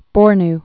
(bôrn)